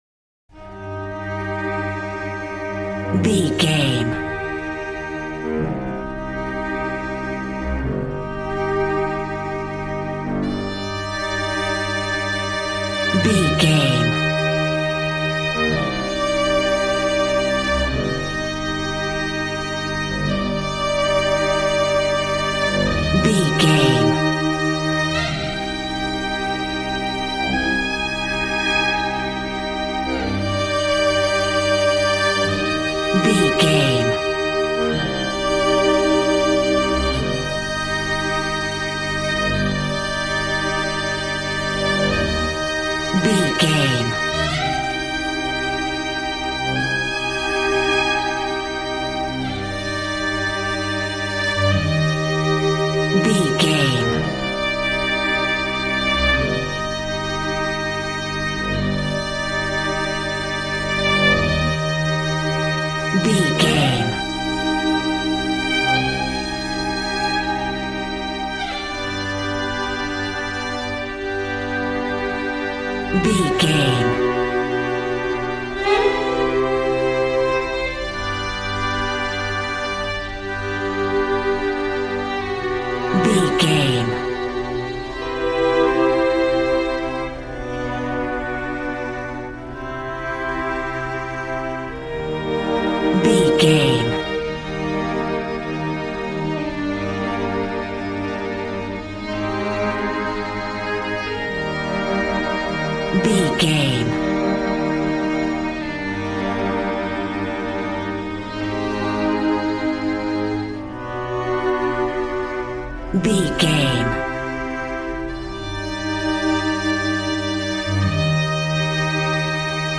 Modern film strings for romantic love themes.
Regal and romantic, a classy piece of classical music.
Aeolian/Minor
regal
cello
violin
brass